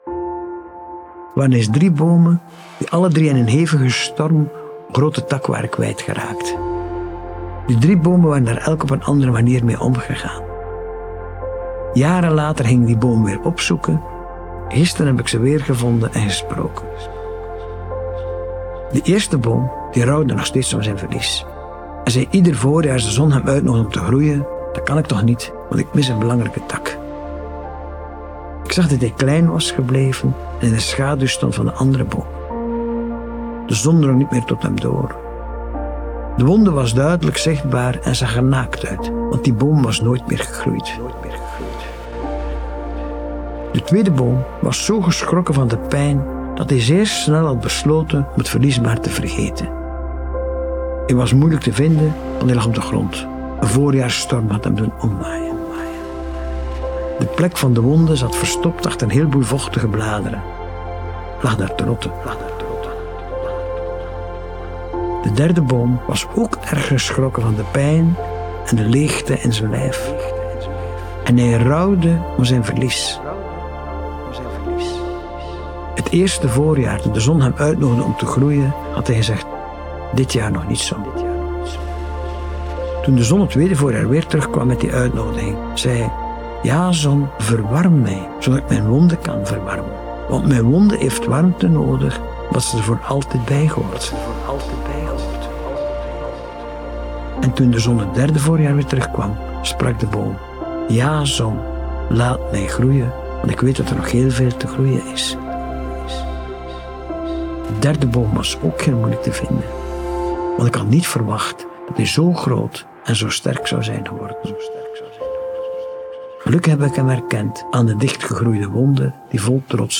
Begin januari 2025 hadden wij het voorrecht om hem te interviewen over zijn inzichten in rouwarbeid. In deze speciale 10e aflevering deelt Manu Keirse uitgebreid zijn kennis en praktische adviezen over het omgaan met verlies.
Interview-Manu-Keirse-Verhaal-van-de-3-bomen-Verborgen-Strijd.mp3